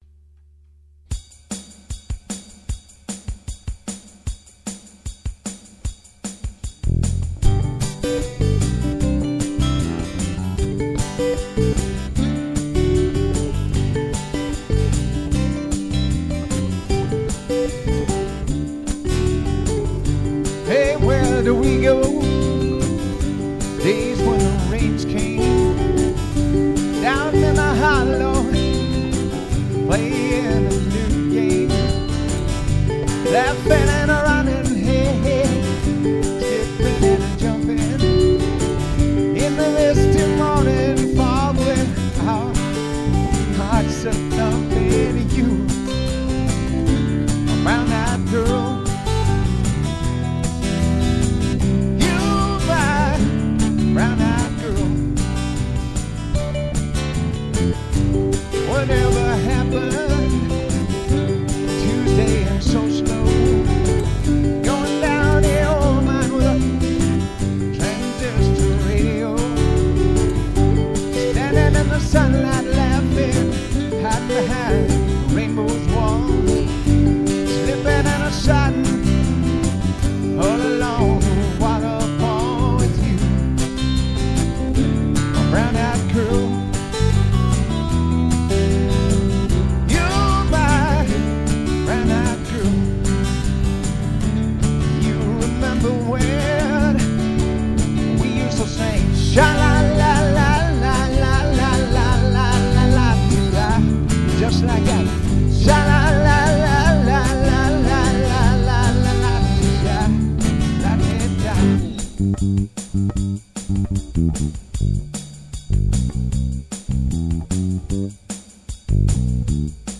Here's the track listing on the disc, all covers:
The drummer is a synthesizer.